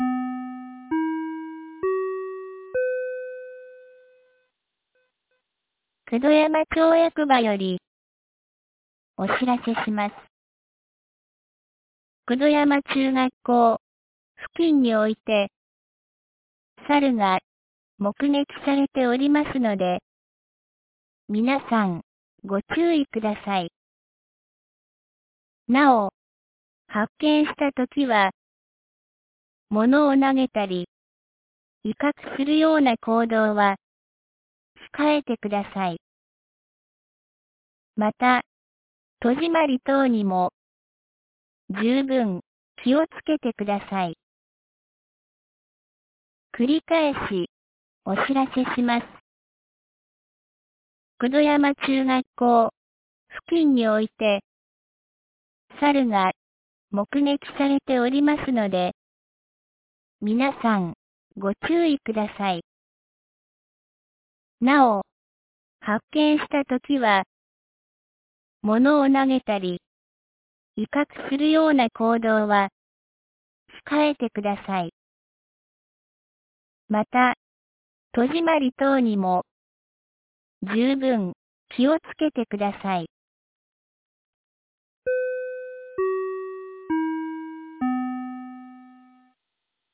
2025年10月03日 16時41分に、九度山町より九度山地区、入郷地区へ放送がありました。